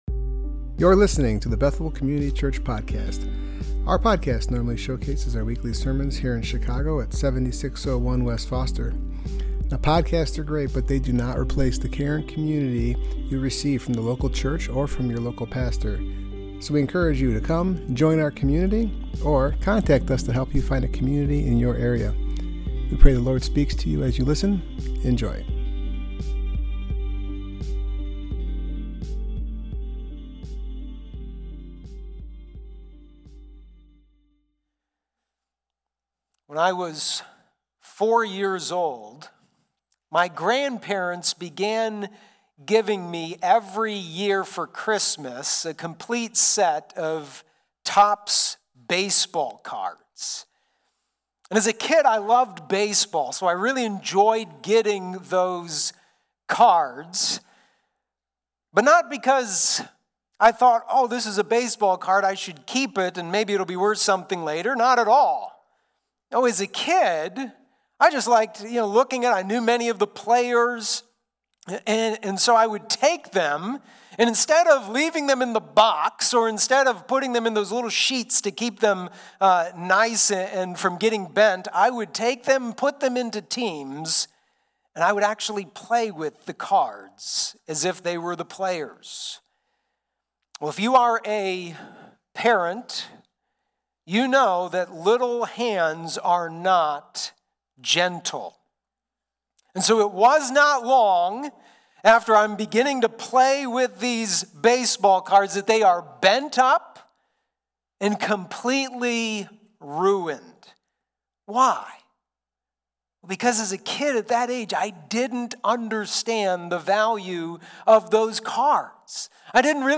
Service Type: Worship Gathering Topics: advent , redemption